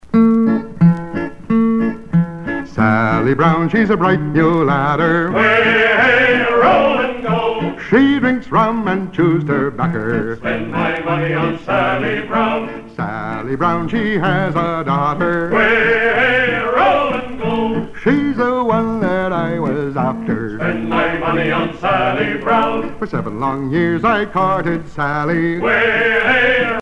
circonstance : maritimes
Pièce musicale éditée